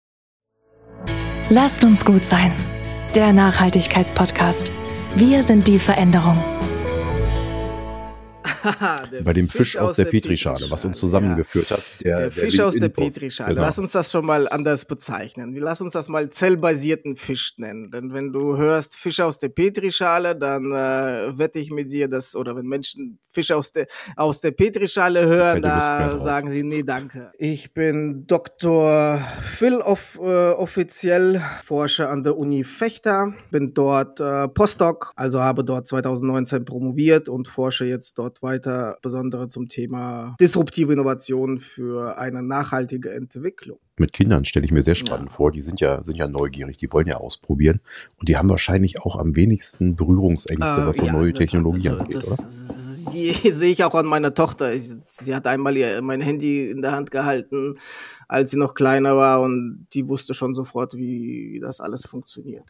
Trailer - ganze Folge ab 13. Januar und 16. Januar 2025 verfügbar